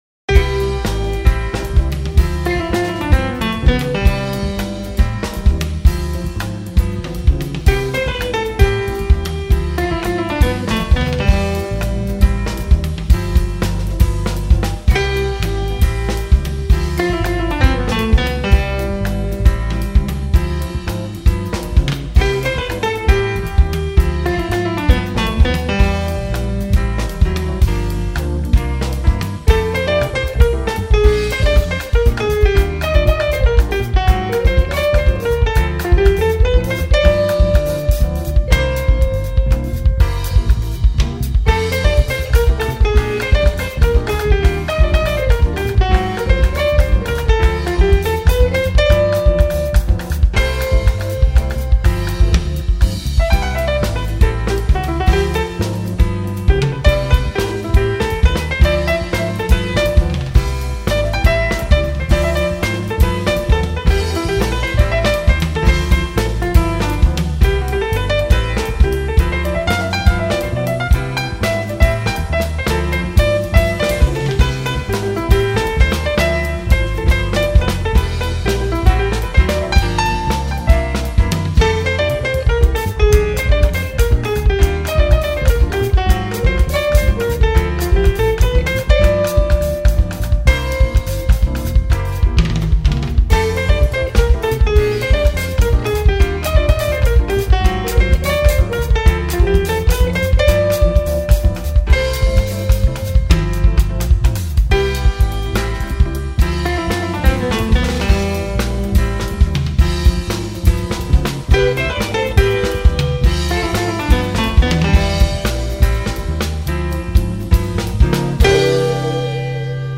514   02:04:00   Faixa: 1    Jazz
Gravado no Teatro 4 de Setembro